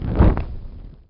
torchon3.wav